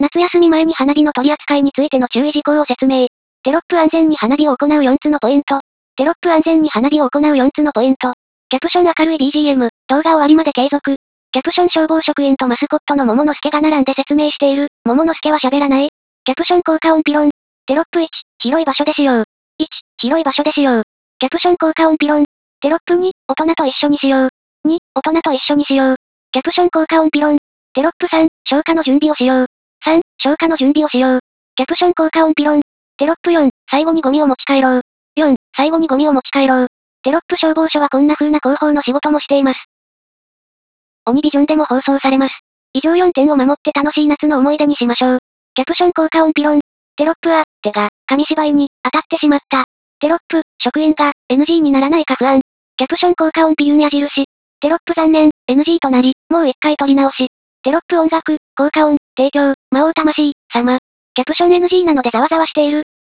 音声解説（ダウンロード）